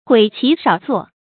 悔其少作 注音： ㄏㄨㄟˇ ㄑㄧˊ ㄕㄠˇ ㄗㄨㄛˋ 讀音讀法： 意思解釋： 謂不滿其年輕時未成熟的作品。